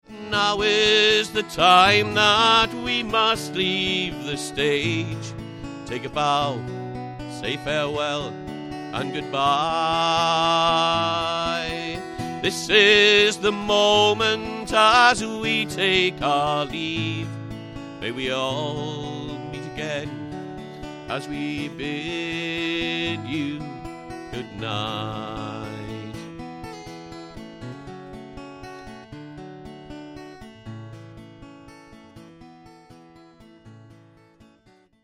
Ashington Folk Club - Spotlight 20 October 2005
sang two of his own compositions